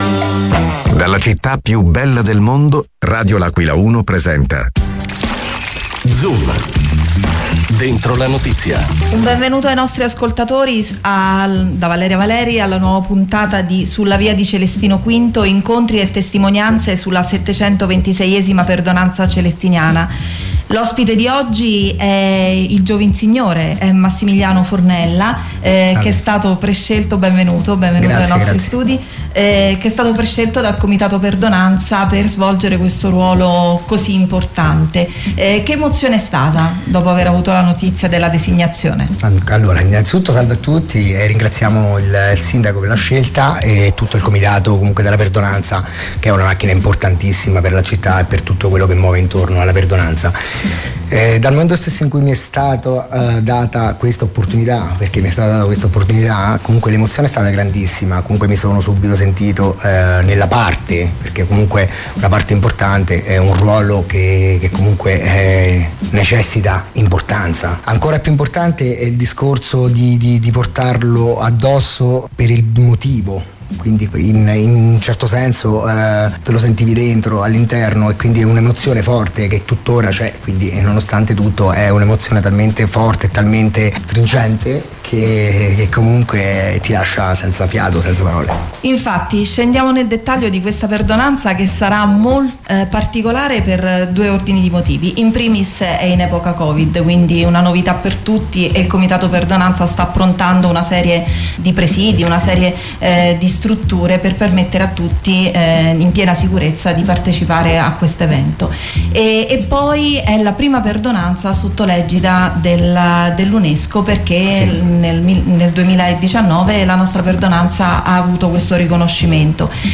ospite negli studi di Radio L’Aquila 1